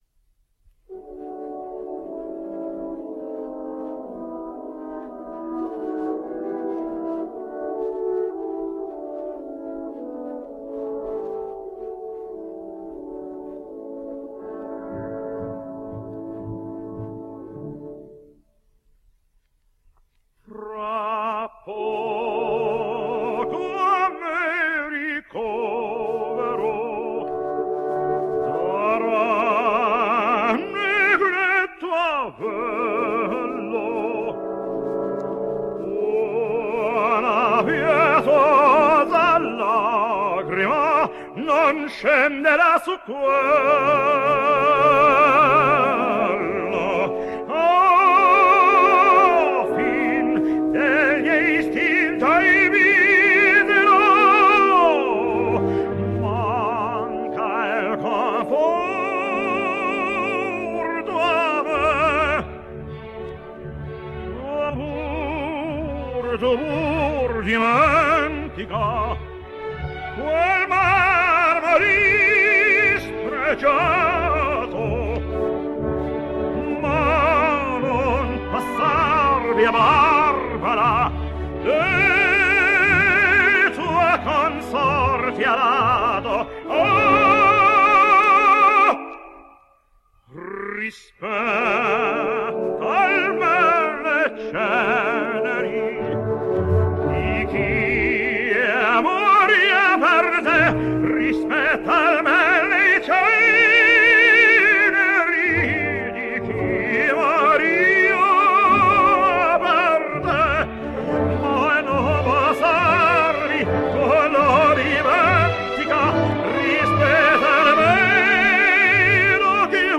American Tenor.
Lucia – Jan Peerce